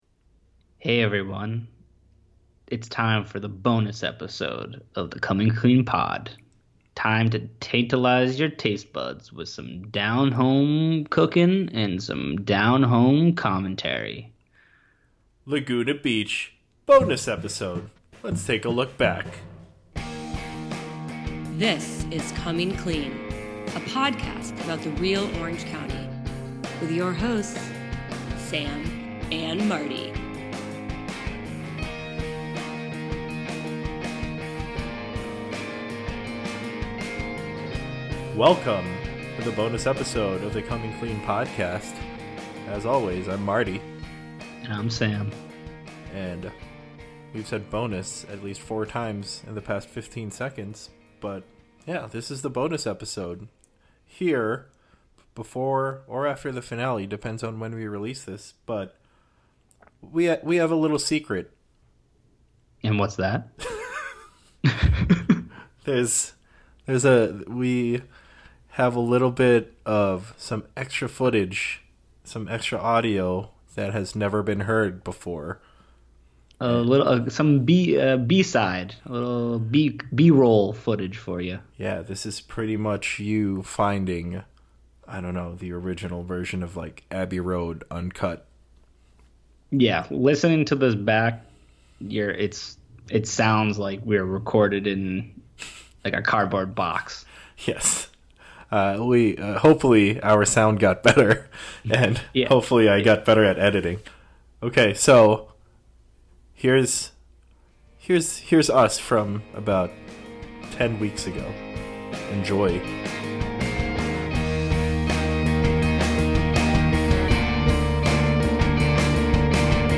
BONUS - Cast Discussion